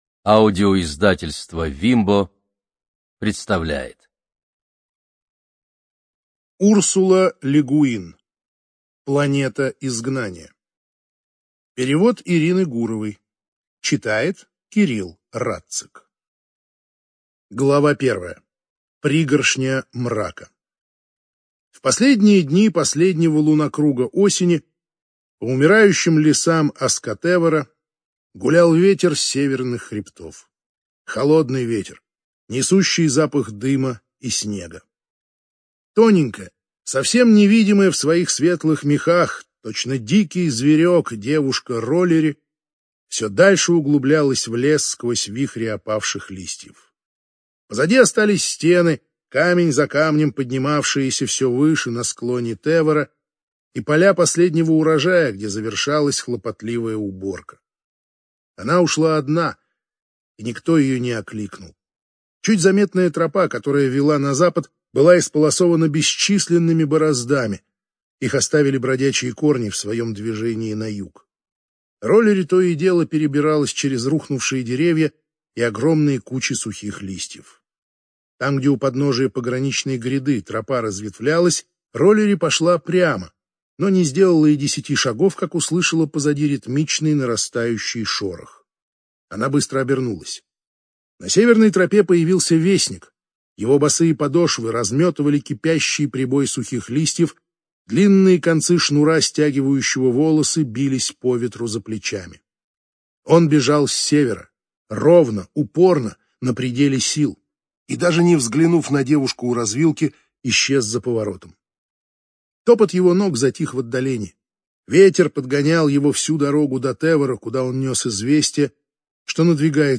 Студия звукозаписивимбо